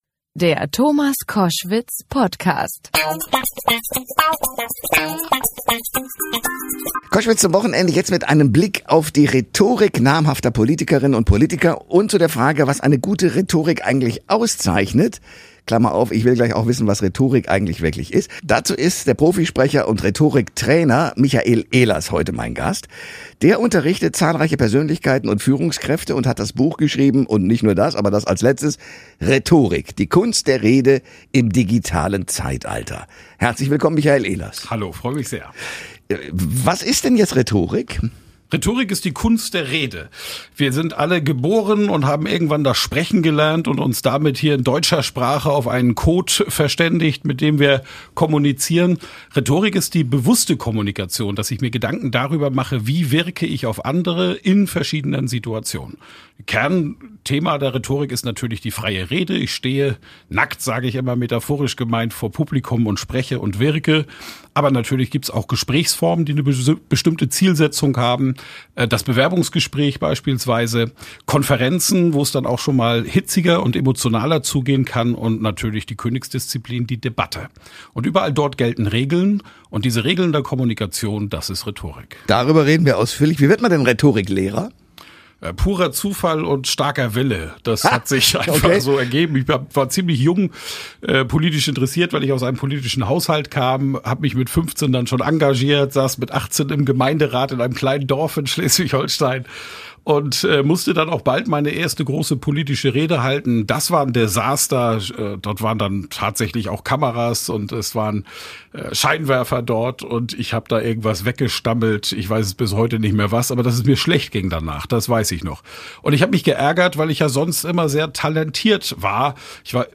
Ich bin vor Freude im Kreise gehüpft, als ich die Einladung zum Format "Koschwitz zum Wochenende" erhalten habe und Gast seiner Radio-Show sein durfte.